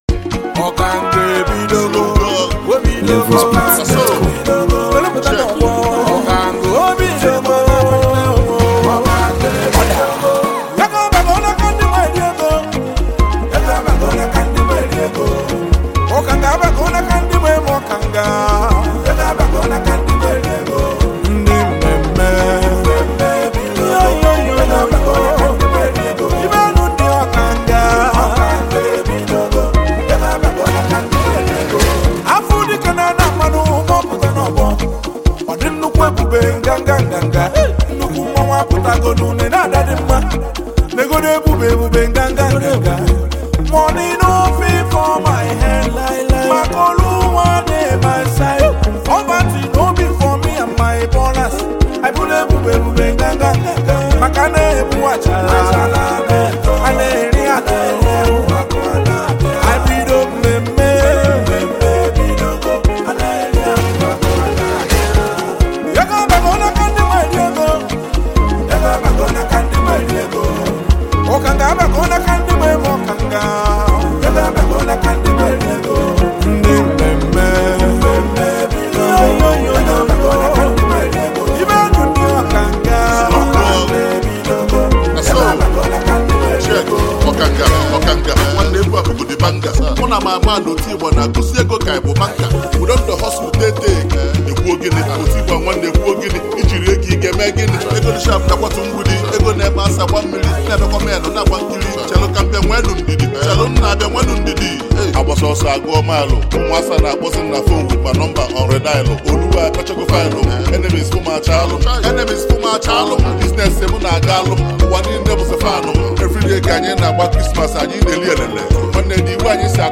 the heavyweight Nigerian highlife and Afrobeat sensation
Blending contemporary highlife rhythms with native Igbo rap
” stands out as a bold and inspirational street anthem.